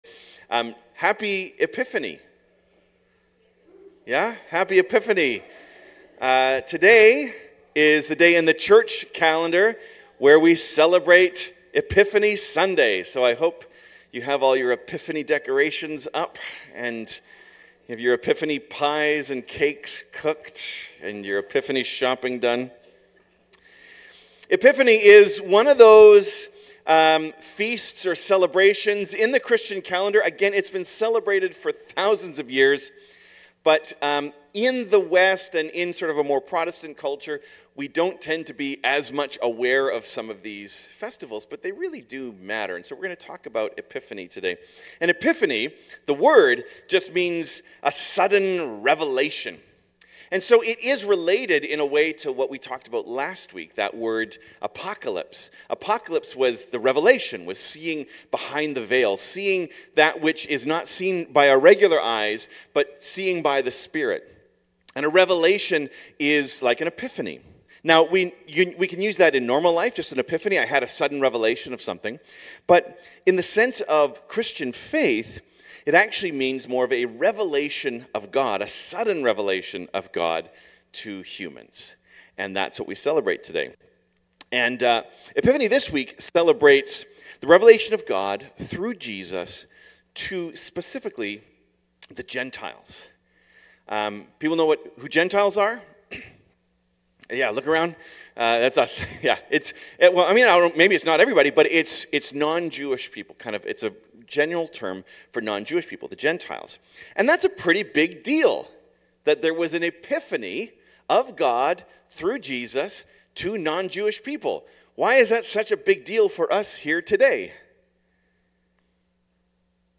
Cascades Church Sermons The Visit of the Magi - Matthew 2 Play Episode Pause Episode Mute/Unmute Episode Rewind 10 Seconds 1x Fast Forward 30 seconds 00:00 / 00:35:55 Subscribe Share Apple Podcasts RSS Feed Share Link Embed